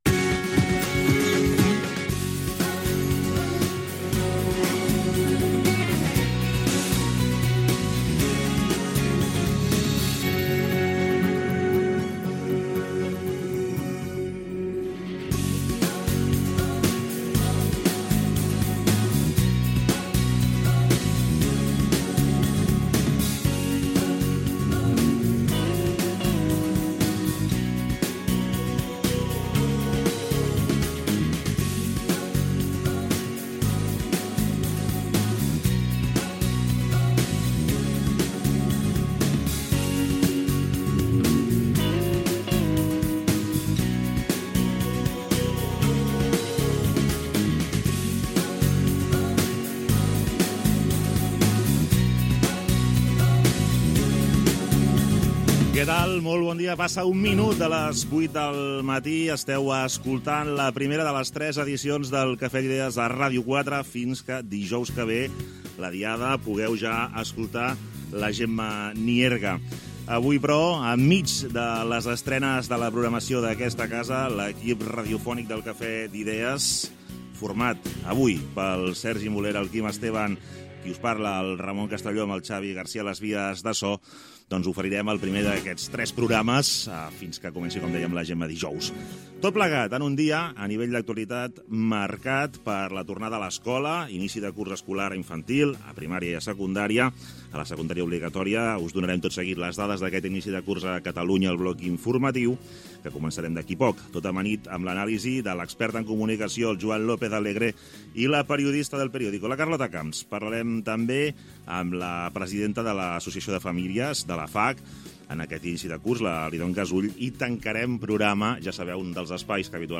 Sintonia del programa, presentació, sumari de continguts, el temps, el trànsit, hora, bloc informatiu
Info-entreteniment